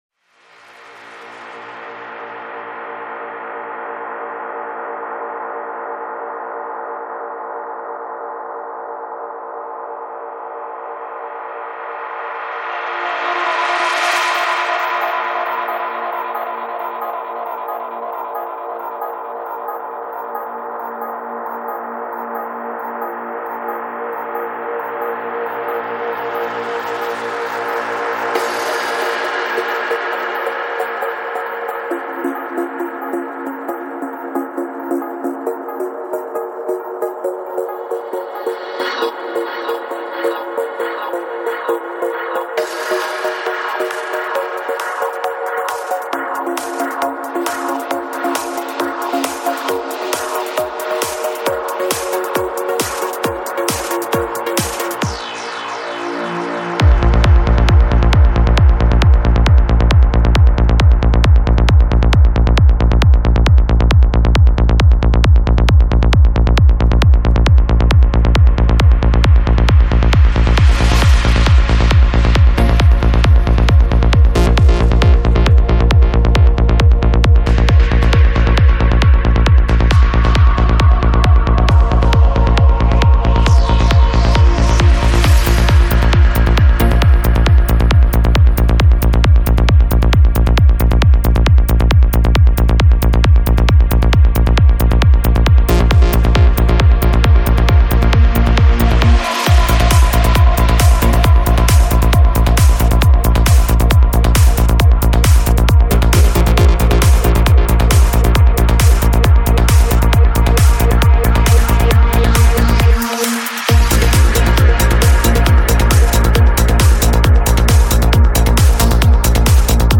Жанр: Psychedelic
Psy-Trance